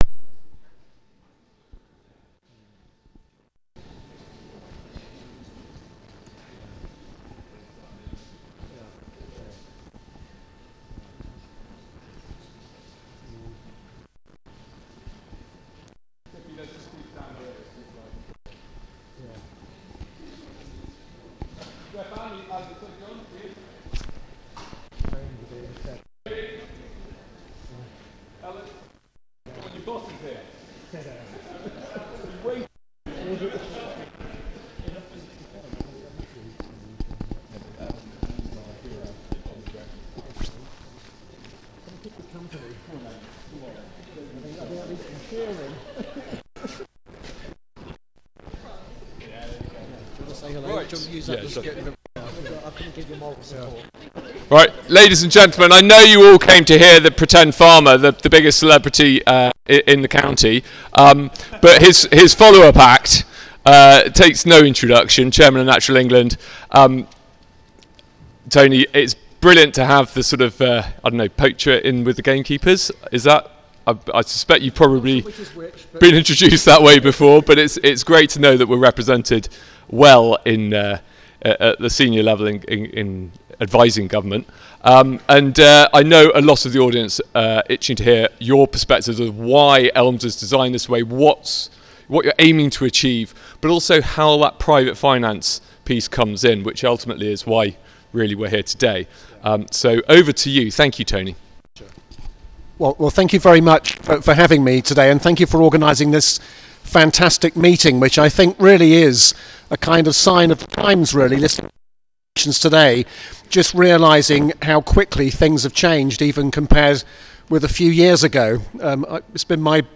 Tony Juniper and panel discussion podcast from Dunthrop Farm event
Please click the link to listen to a recording of Tony Juniper's talk and the subsequent panel discussion of experts.